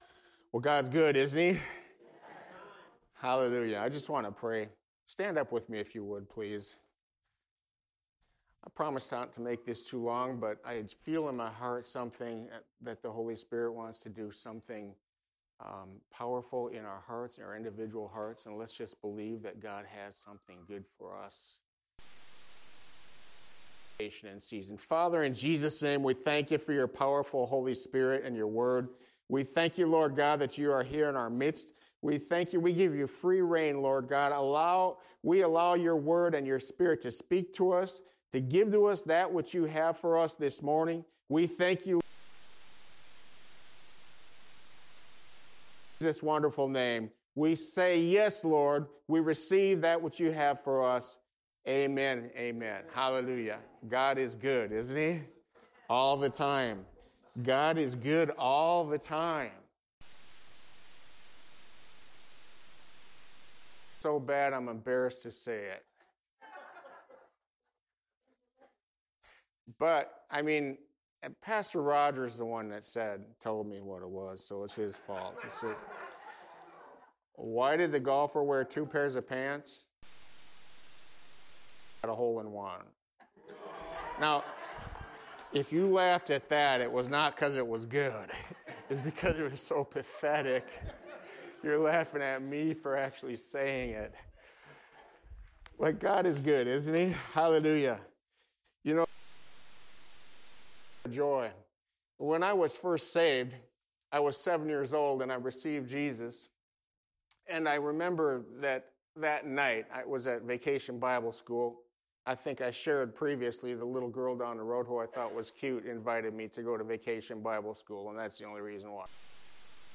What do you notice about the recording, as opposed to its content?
1 Corinthians 14:1 Service Type: Sunday Service Why is God’s love beyond reason?